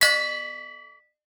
Boxing Bell.wav